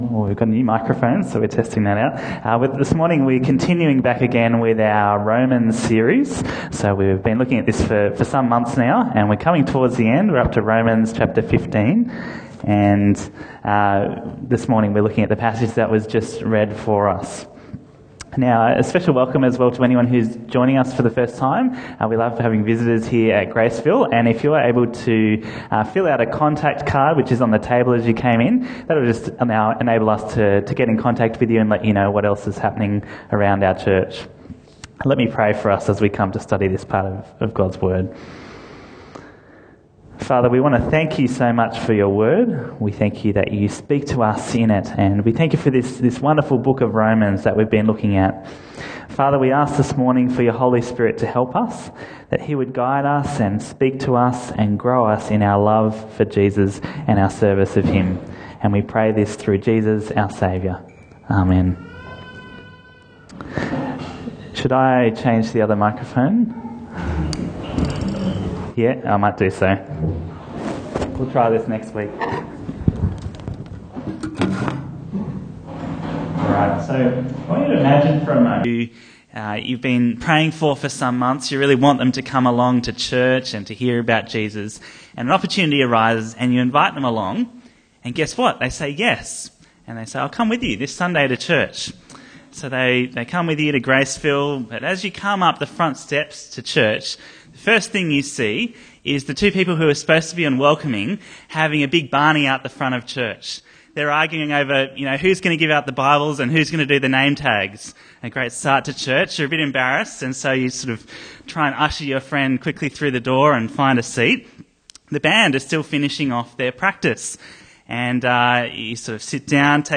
Bible Talks Bible Reading: Romans 15:1-13